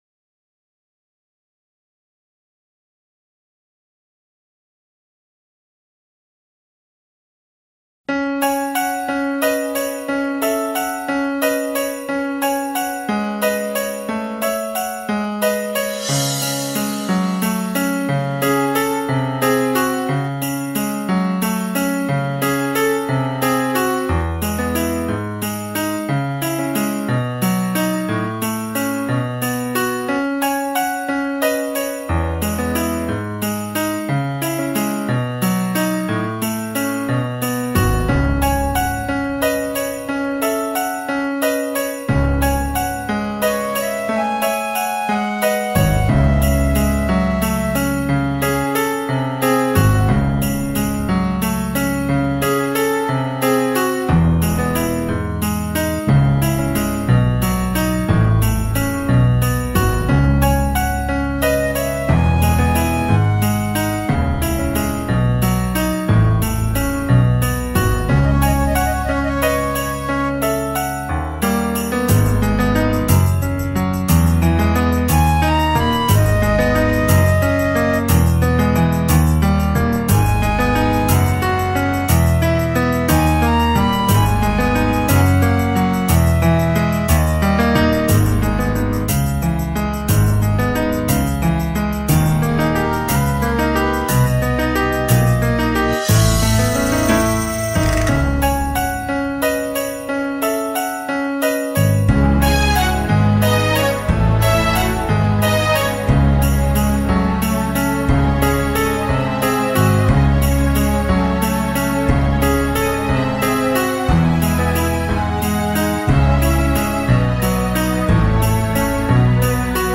El acompañamiento: